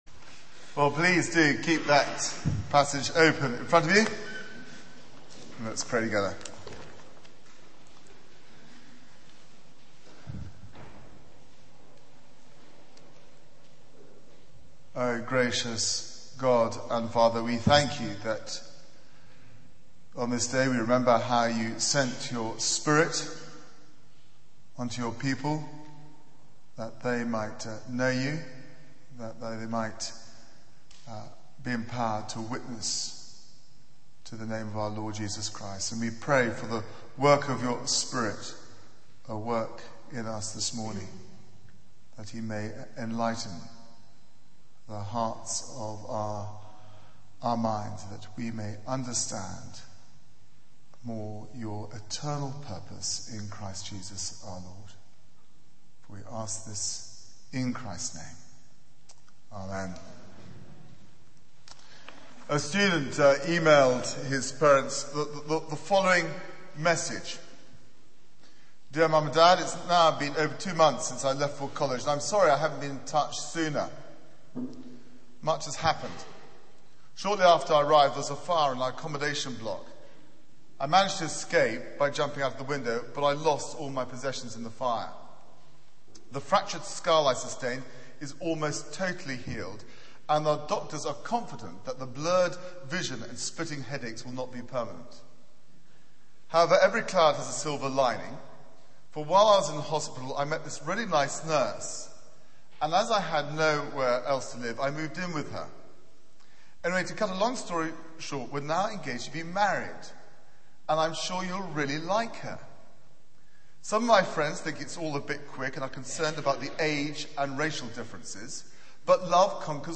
Media for 9:15am Service on Sun 23rd May 2010 09:15 Speaker
Theme: 'History - Eternity' Sermon